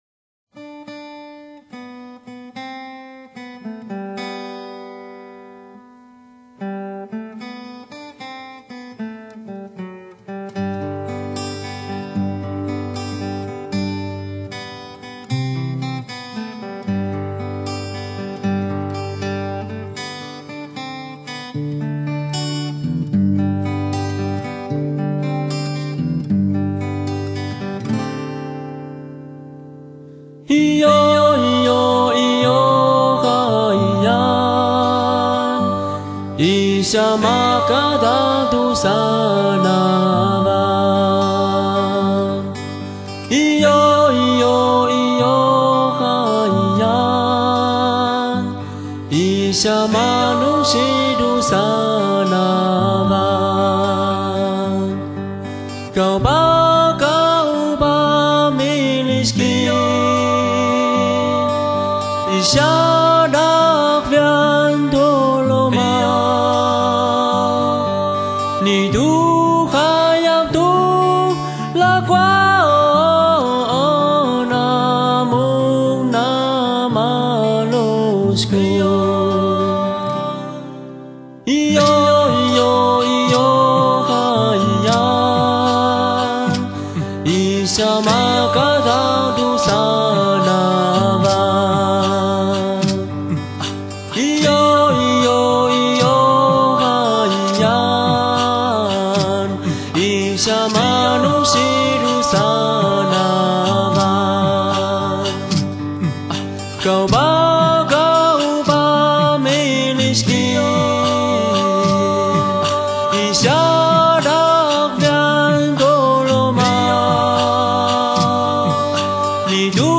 現在，他以一把木吉他和清泉般的歌聲，唱出最清新卻最撞擊人心的歌聲，是新民歌浪潮中最值得期待的創作歌手！